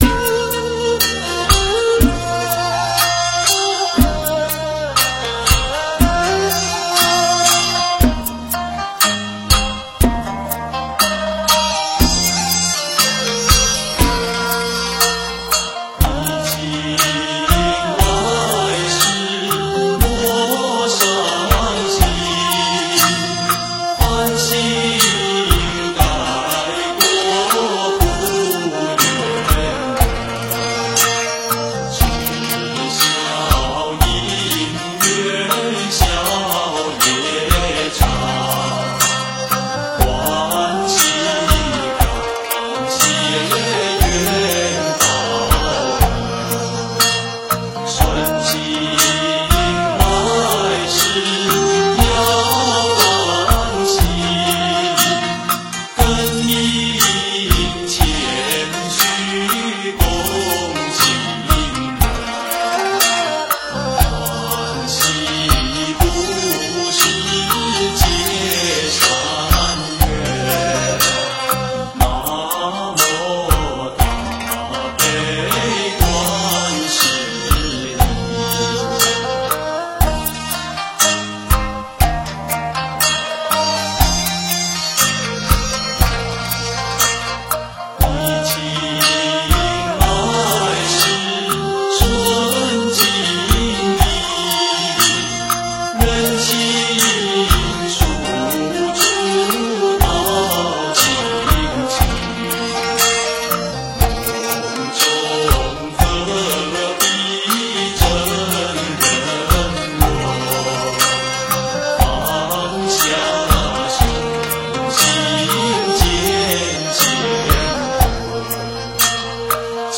心静如水 诵经 心静如水--佛教音乐 点我： 标签: 佛音 诵经 佛教音乐 返回列表 上一篇： 无邪新生 下一篇： 一声佛号一声心 相关文章 药师佛心咒--北京快乐小菩萨合唱团 药师佛心咒--北京快乐小菩萨合唱团...